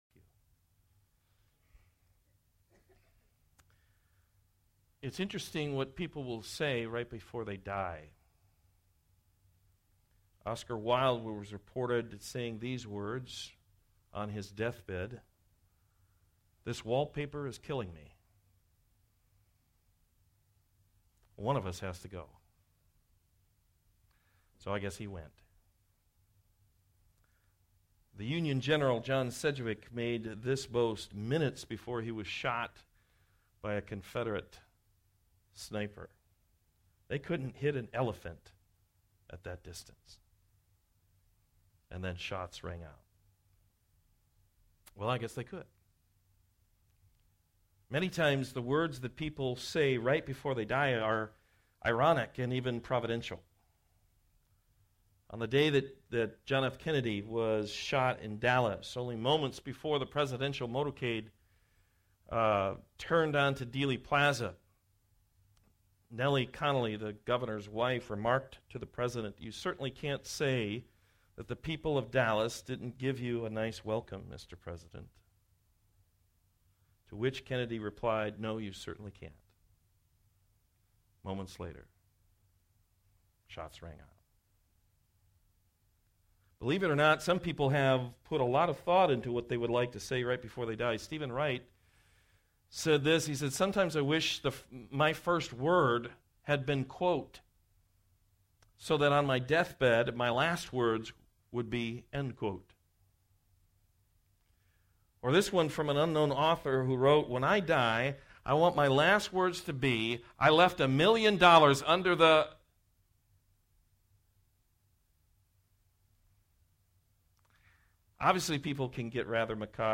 2 Timothy 4:1-5 Service Type: Morning Service Fulfill the mission God has given to you!